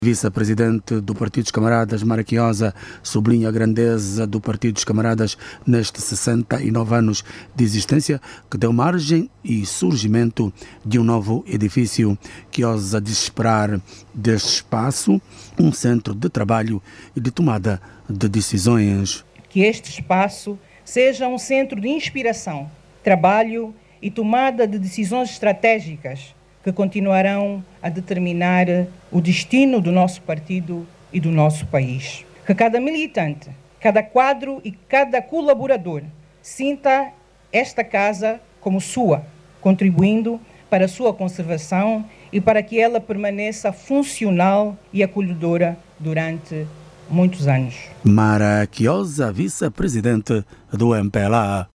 A Vice-Presidente do MPLA, Mara Quiosa, tem na grandeza e dimensão do novo edifício, razões bastantes para concluir que estão reunidas muito mais ainda as condições para um trabalho modernizado e estratégico. Ouça no áudio abaixo toda informação com a reportagem